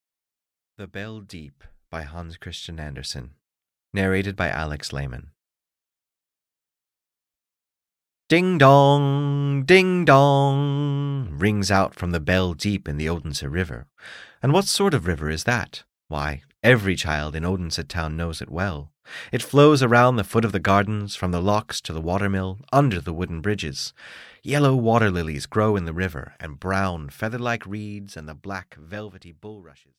Audio knihaThe Bell Deep (EN)
Ukázka z knihy